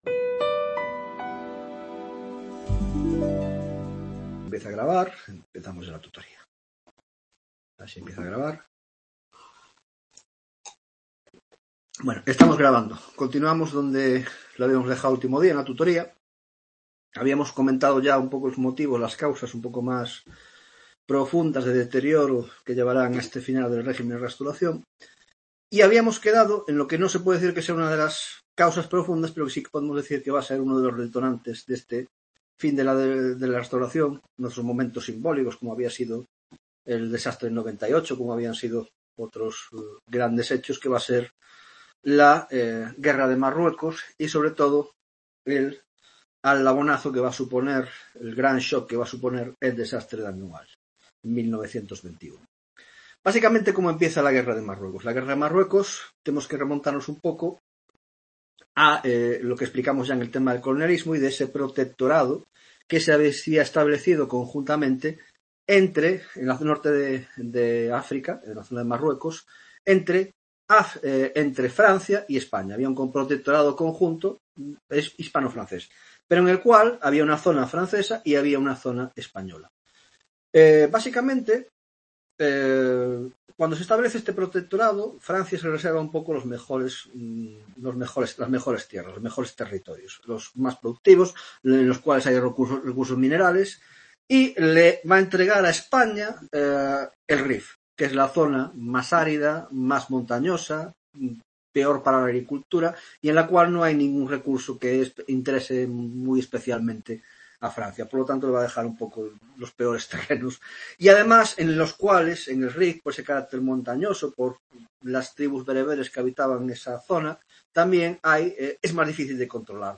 15ª tutoria de Historia Contemporánea